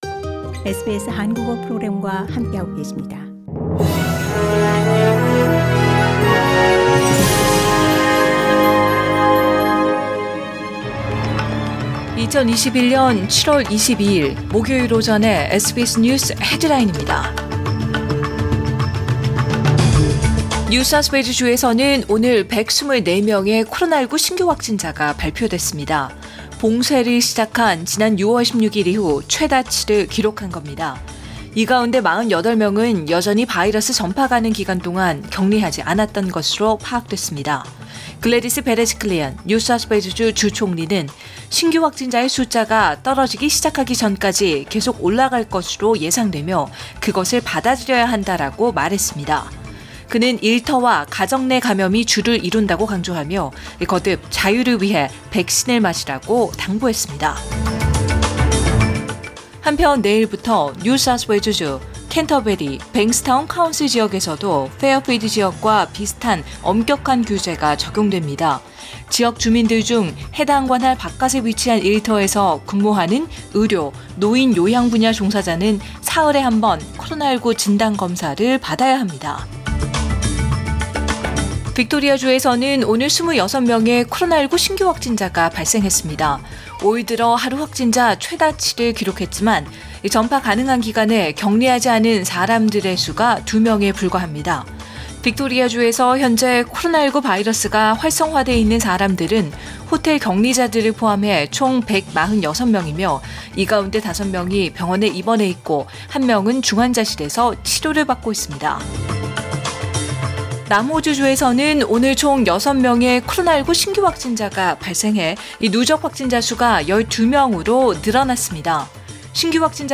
2021년 7월 22일 목요일 오전의 SBS 뉴스 헤드라인입니다.